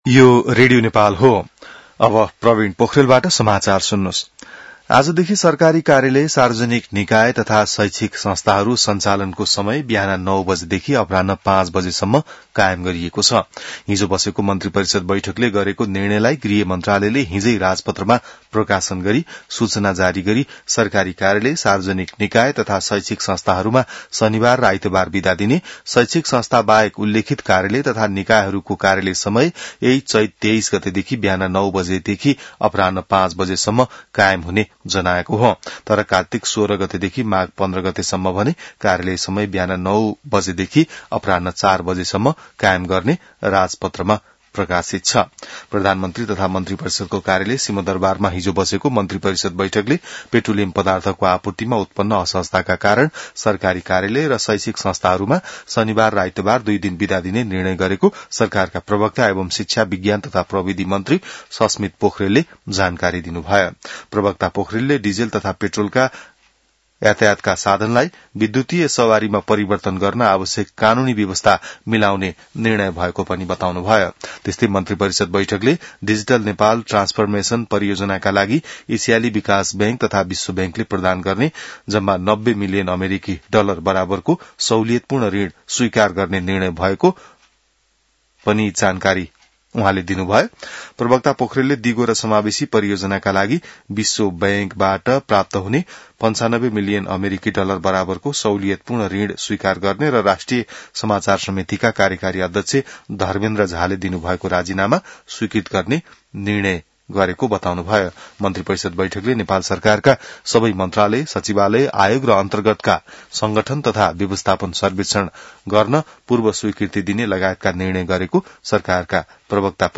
An online outlet of Nepal's national radio broadcaster
बिहान ६ बजेको नेपाली समाचार : २३ चैत , २०८२